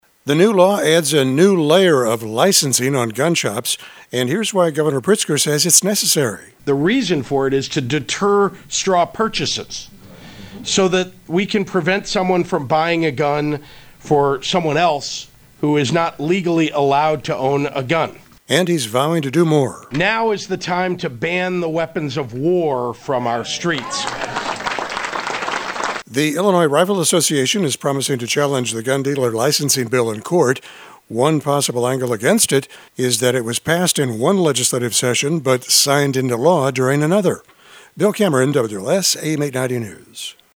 (CHICAGO)  At a bill-signing event featuring the state’s top Democrats,  Governor Pritzker today signed the gun dealer licensing bill that Bruce Rauner would have vetoed if Democrats had put it on his desk when he was governor.
The new law adds a new layer of licensing on gun shops and here’s why Gov Pritzker says it’s necessary.